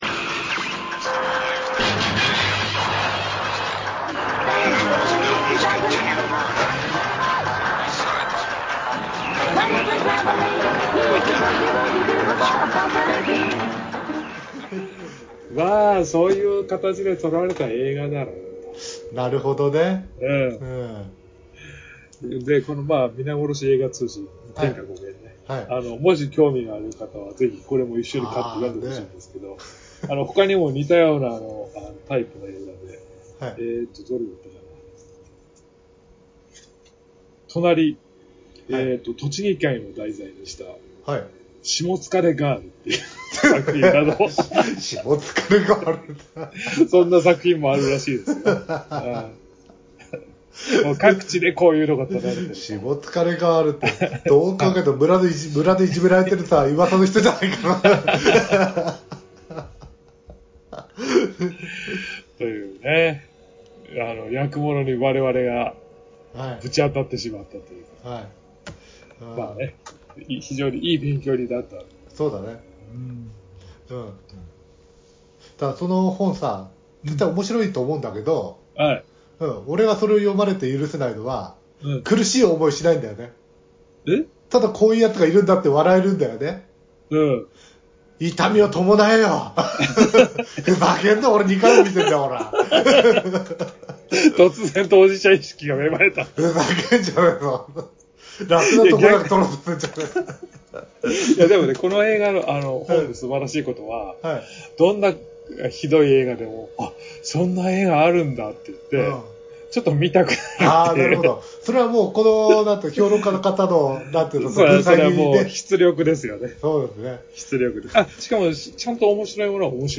アメコミやバンドデシネがちょっと好きで、ちょっとアレな二人の男子が、至高の女子会を目指すエンタテインメントネットラジオです。